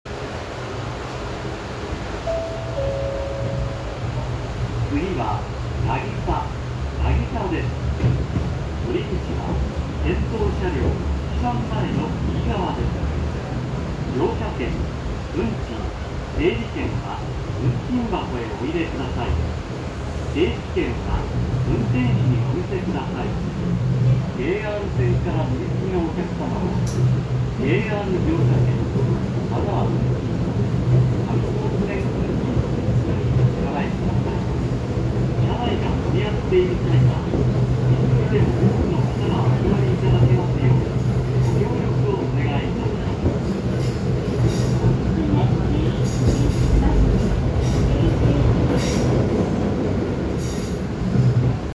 ・3000形車内放送
【１】始発時（新島々発車時）
下り列車は男声、上り列車は女声の放送で、この他に始発時に流れる放送は恐らくレゾナントの物と思しき時々バスで聞くチャイム＋上りと同じ女声の放送、となっています。